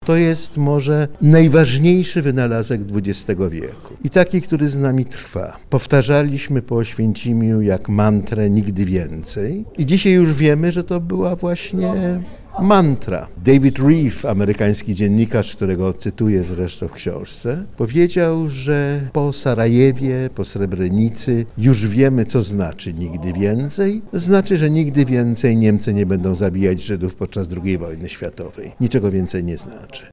ZOBACZ ZDJĘCIA: Spotkanie z Konstantym Gebertem odbyło się w Ośrodku „Brama Grodzka – Teatr NN” .